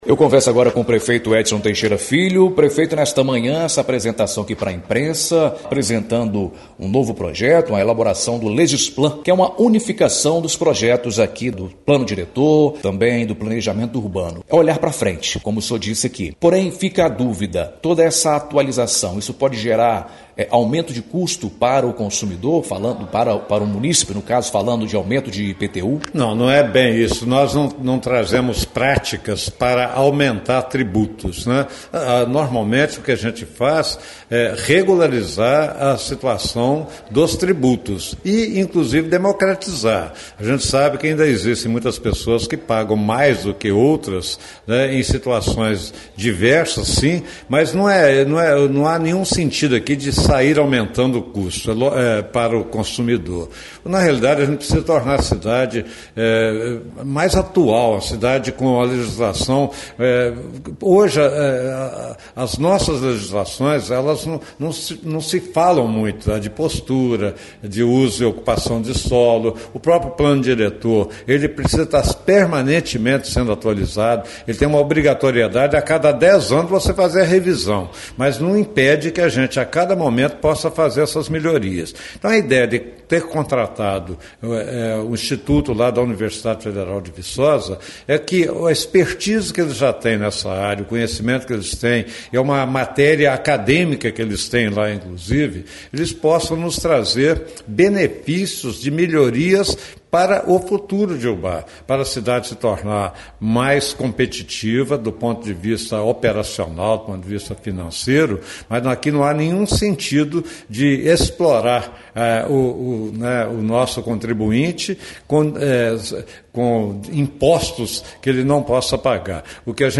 Entrevista Prefeito Edson Teixeira FIlho